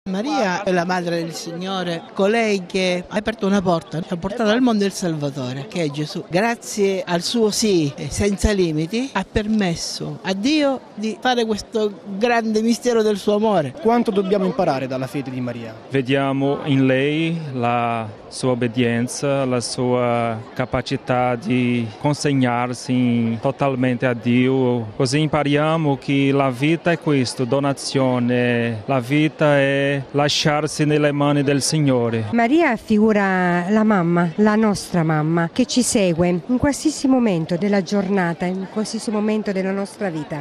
Ascoltiamo i commenti di alcuni fedeli in Piazza San Pietro